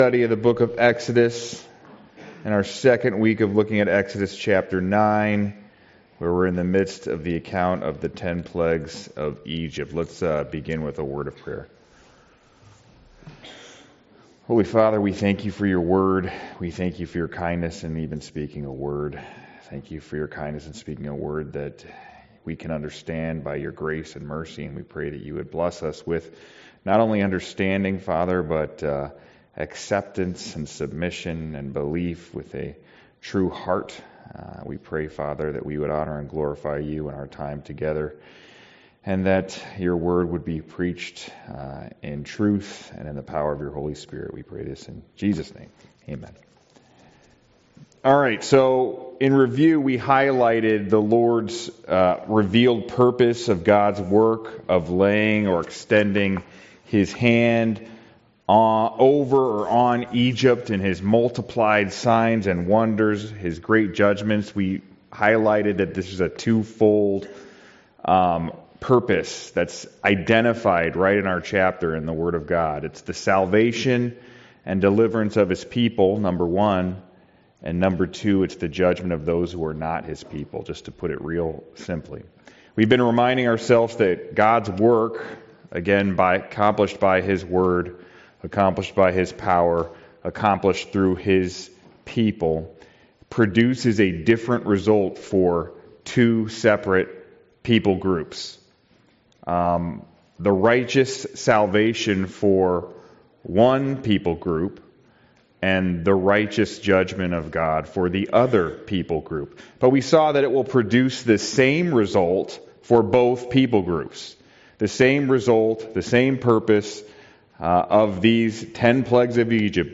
Studies in Exodus Passage: Exodus 9 Service Type: Sunday School « Sorrento